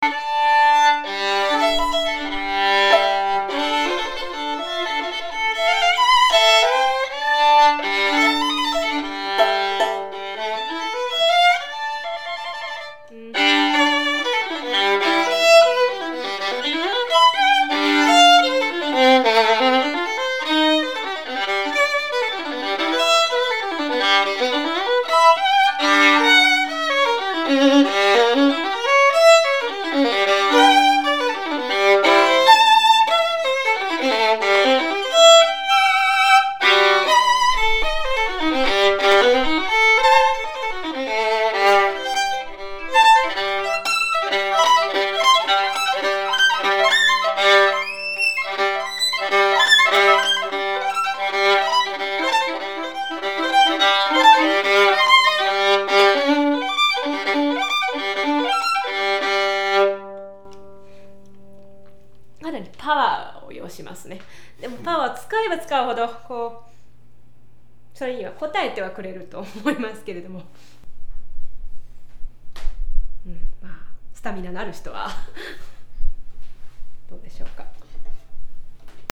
ホーム ｜ バイオリン > Forest工房ClassDForestViolin工房ClassDツーピースバック